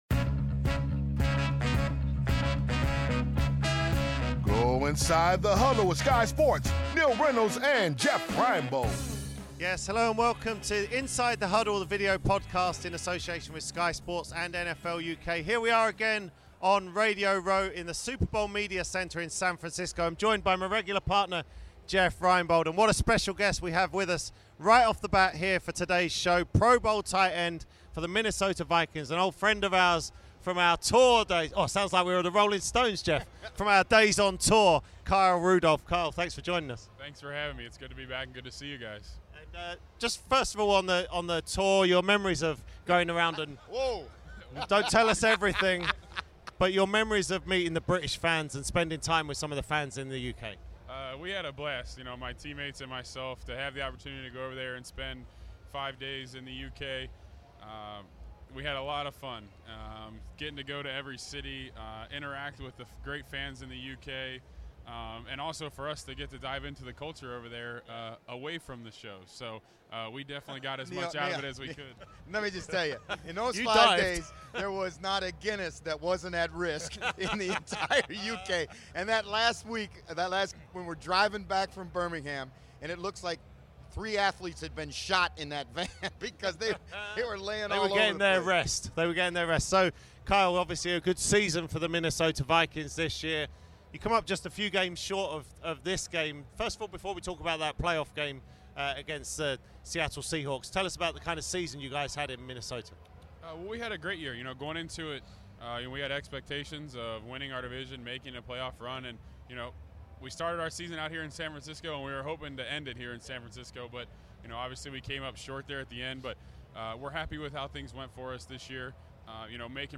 present another show from Radio Row in San Francisco for Super Bowl week. On this episode the guys are joined by Minnesota Vikings tight-end, Kyle Rudolph & former NFL safety, Nick Ferguson.